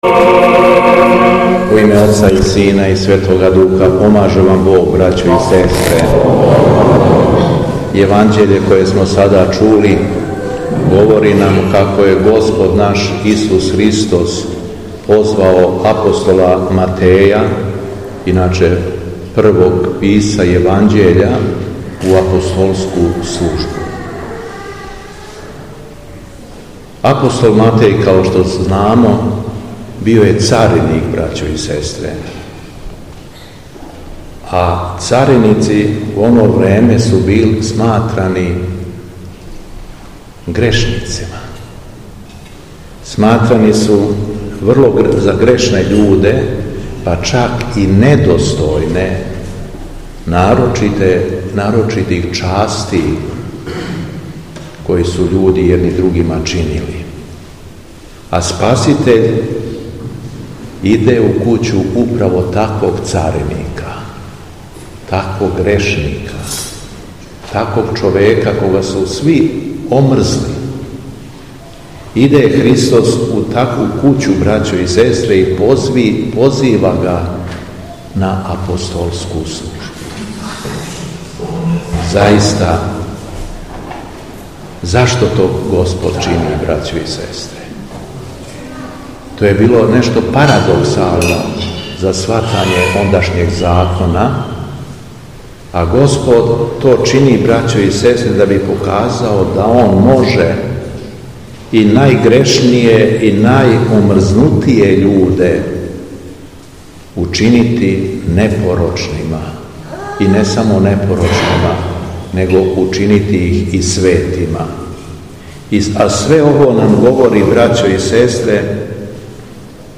Беседа Његовог Високопреосвештенства Митрополита шумадијског г. Јована
Након прочитаног јеванђеља Митрополит се сабраним верницима обратио беседом: